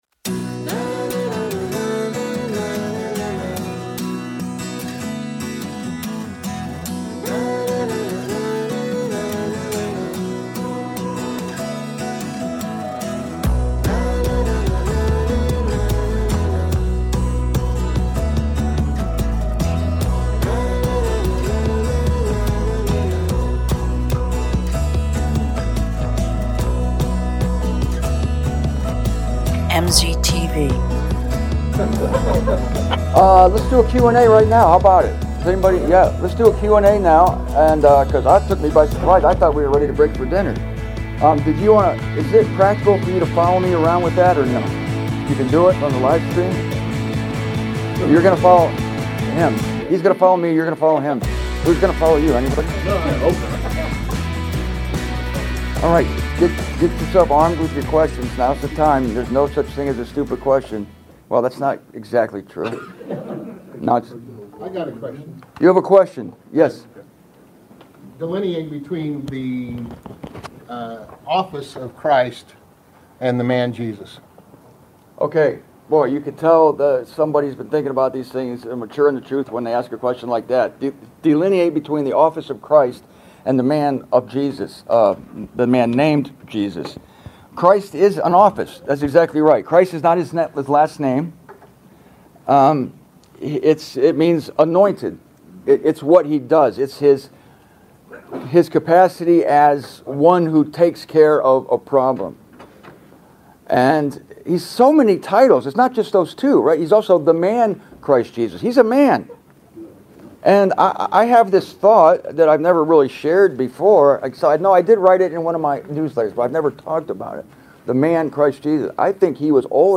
Here is the first Q&A session from the Phoenix, Arizona conference in May of this year.